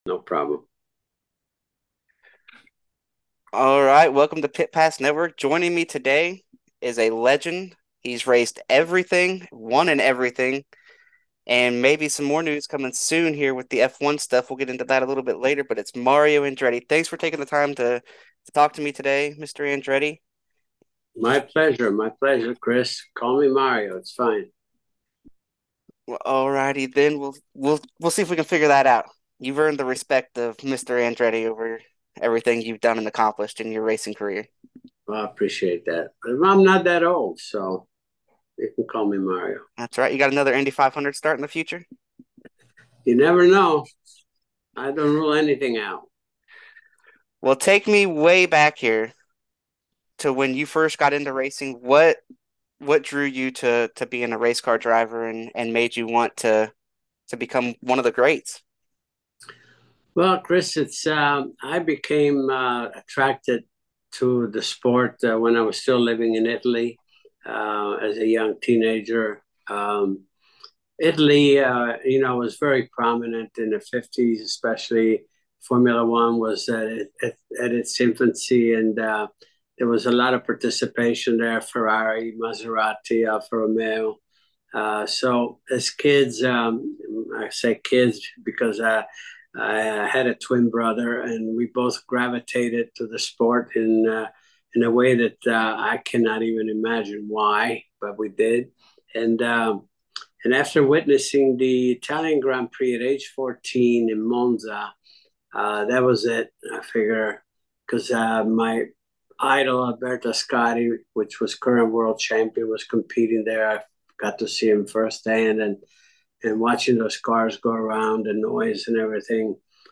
Pit Pass Network Interview: Mario Andretti - Pit Pass Network
Click below as the racing icon talks with Pit Pass Network about F1 and IndyCar.